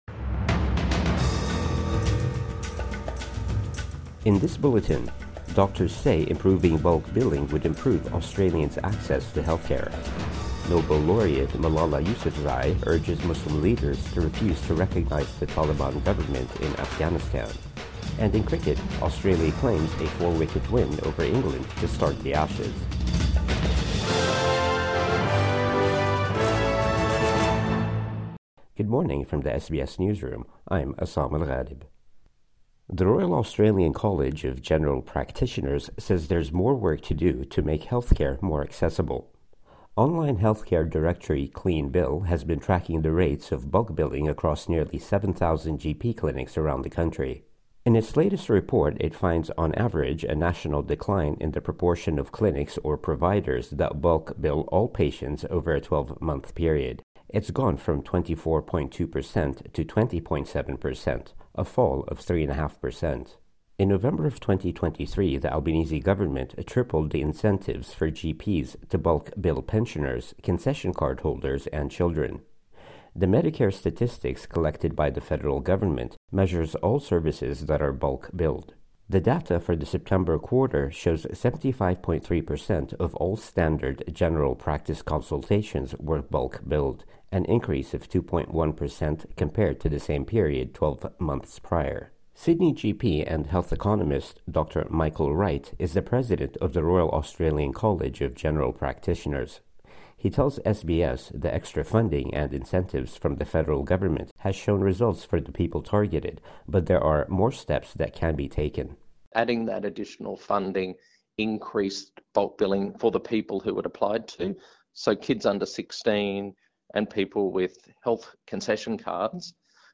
Morning News Bulletin 13 January 2025